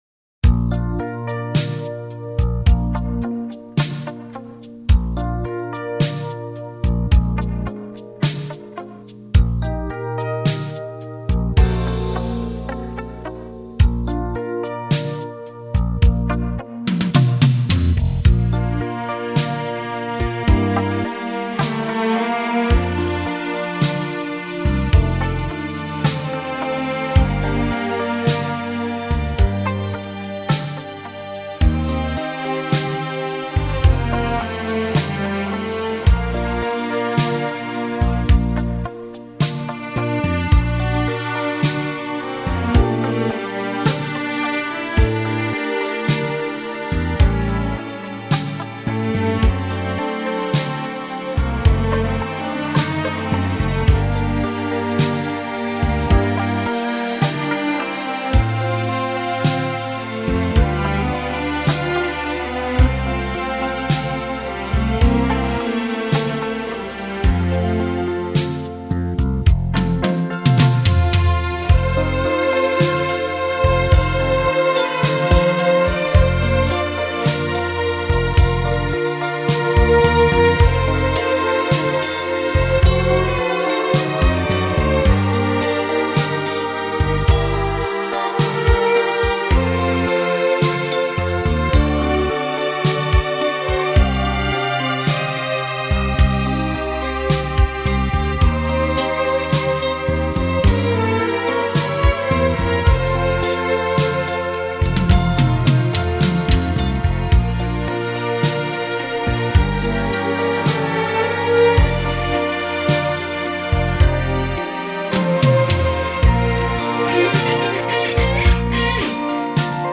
Ave Maria. instrum.